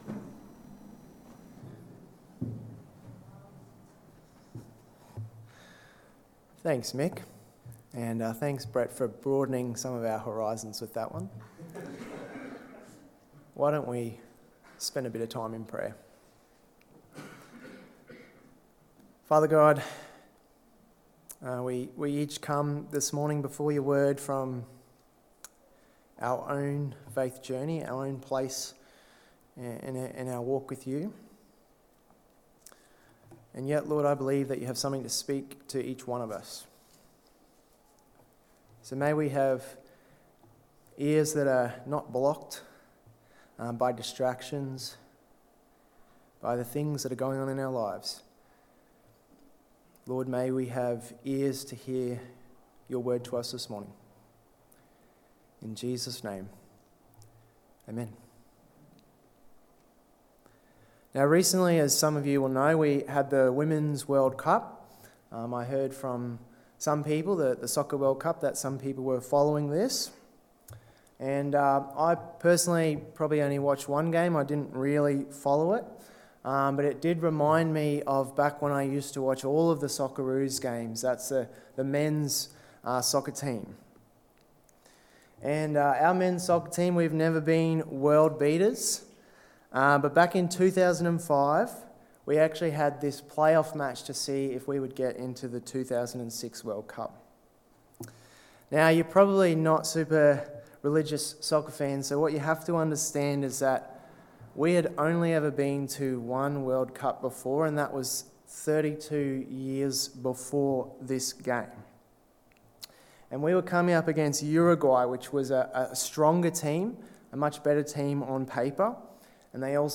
Sermons | Tenthill Baptist Church